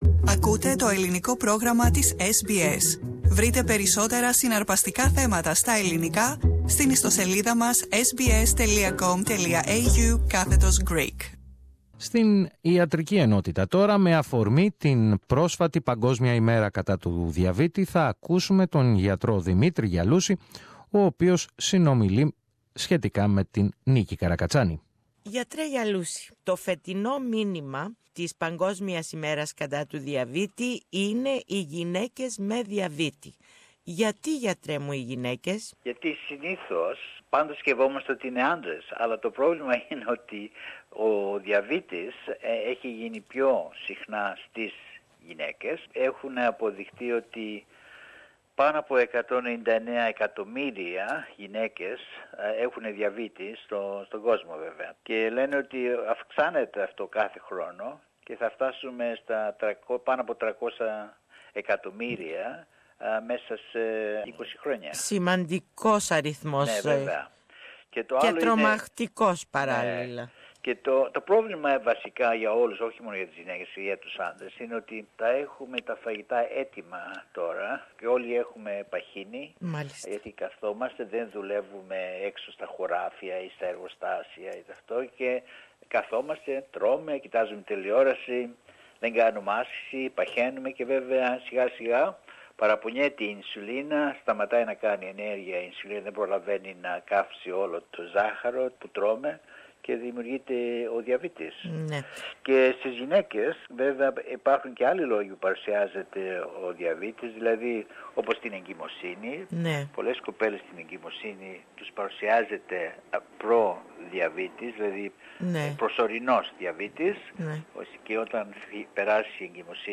More in this interview that Greek-Australian doctor